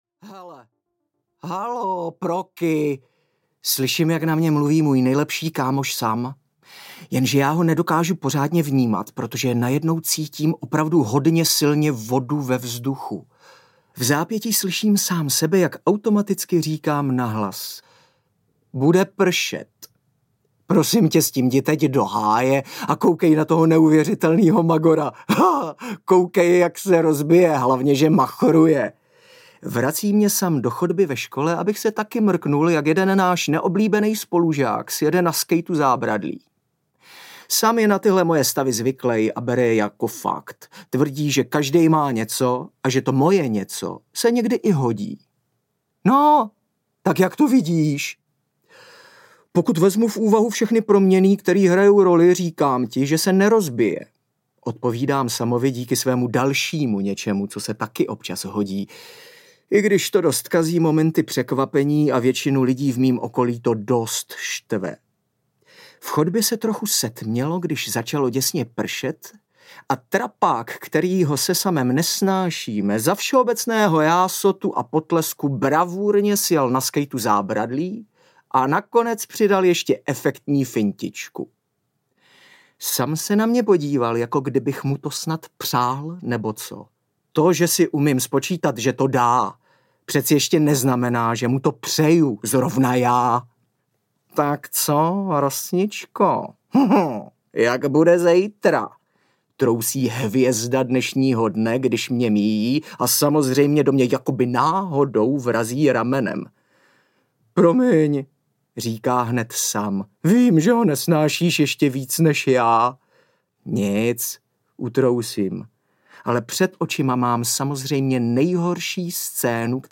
Tajemství planety Dor audiokniha
Ukázka z knihy